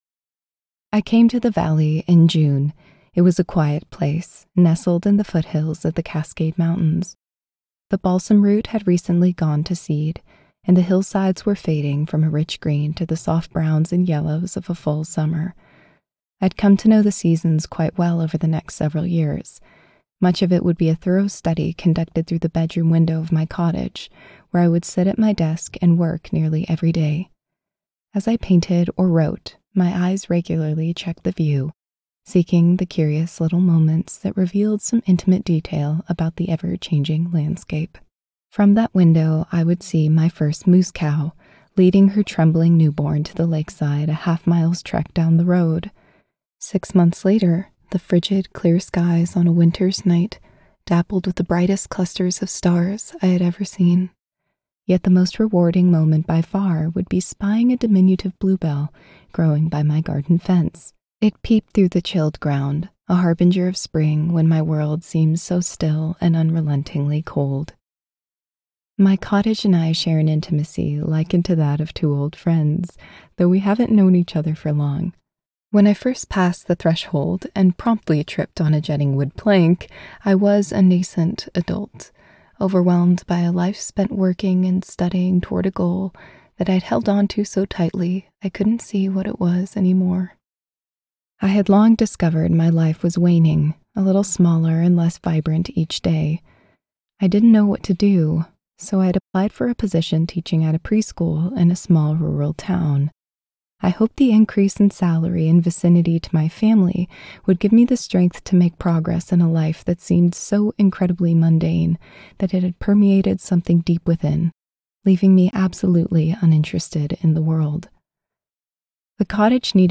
Cottage Fairy Companion - Vibrance Press Audiobooks - Vibrance Press Audiobooks